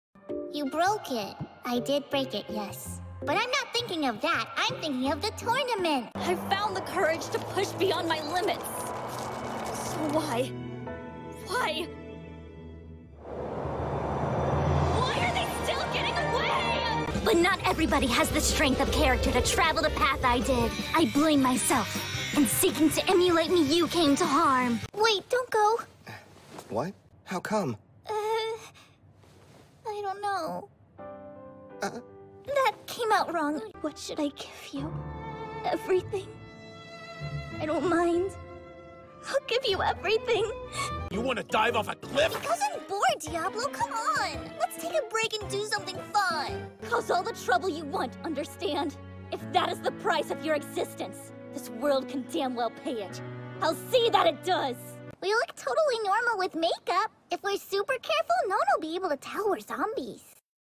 Voiceover
Animation
• Accents/Dialects
• Character Voices